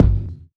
break_kick_4.wav